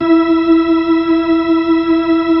Index of /90_sSampleCDs/Keyboards of The 60's and 70's - CD2/B-3_Full Fast/B-3_Full Fast